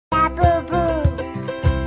labubuuuuuu Meme Sound Effect